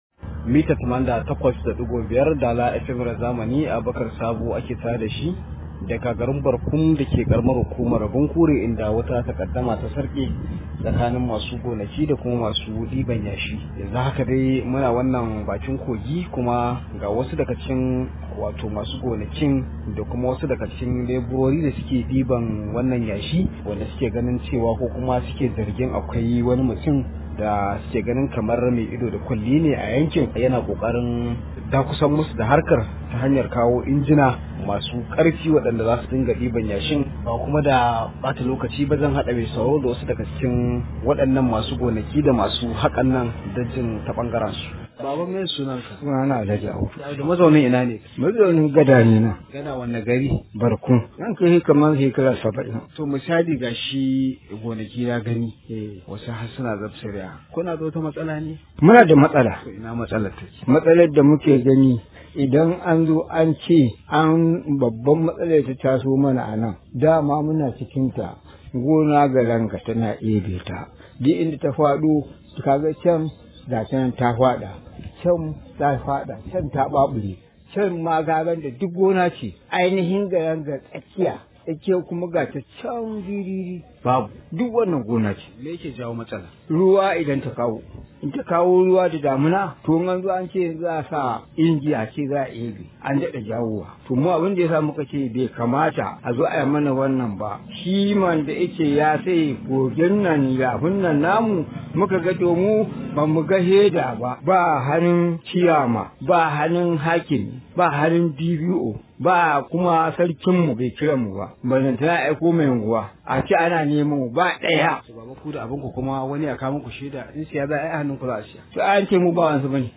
Rahoto: Kwace mana Kogi zai janyo rashin aikin yi ga matasan mu – Al’ummar Barkum